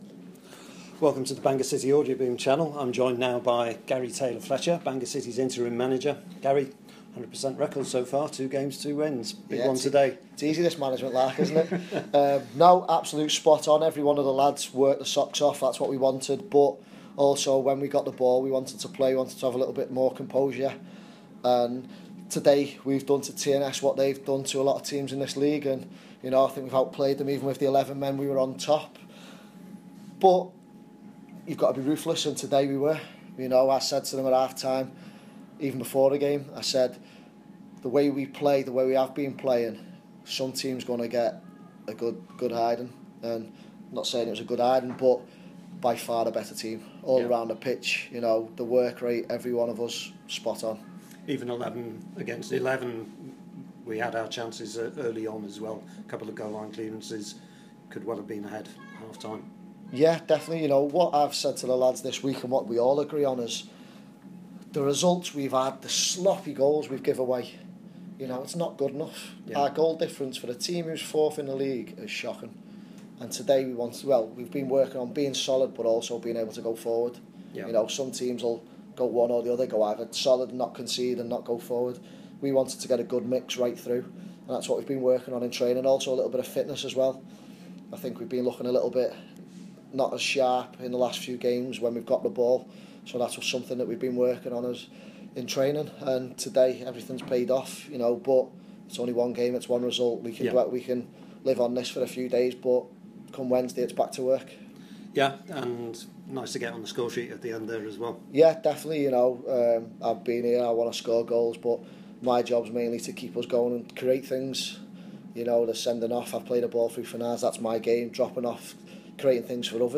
Citizens Interview